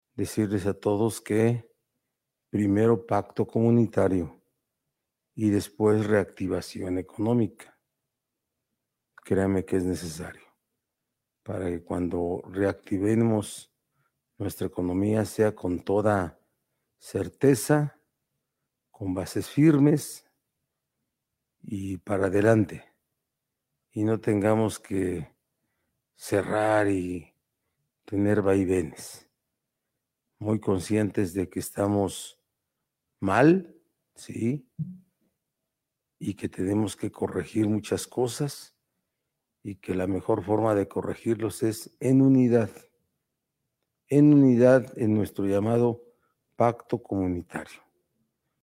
Así lo expuso el gobernador Miguel Barbosa Huerta, en la habitual conferencia de prensa que preside en Casa Aguayo, en la que reportó 222 nuevos casos de coronavirus y 42 rezagados, así como 23 decesos en las últimas 72 horas, a los que se suman 19 acumulados.